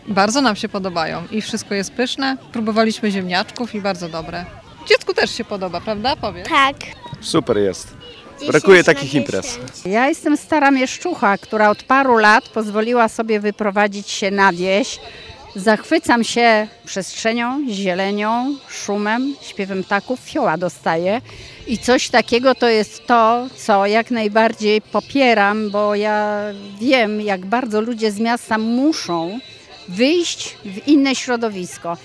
Dziś półmetek Pikniku Majowego w Agrodolince w podstargardzkim Gogolewie.